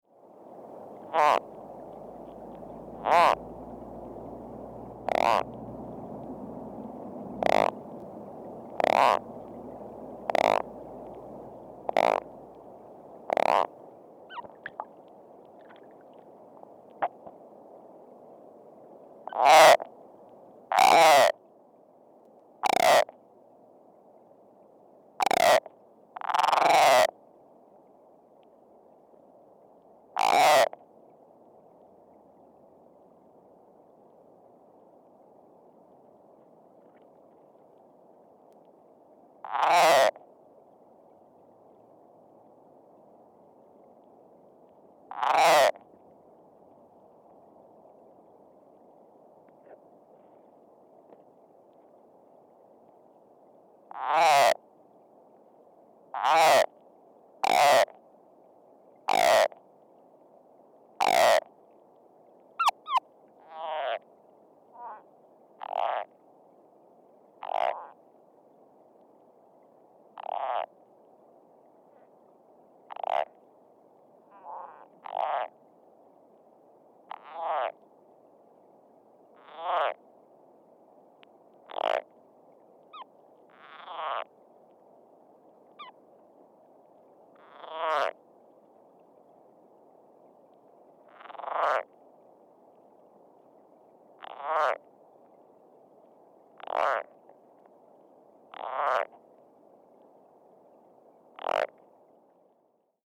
The following recording was made with an underwater microphone at the same location as above, but two years later, on a sunny afternoon.
Sounds  This is a 1 minute and 43 second recording of a frog made with an underwater microphone. The frog begins calling in the air with its head out of the water and its throat sack on the surface of the water. He then dives underwater and continues calling.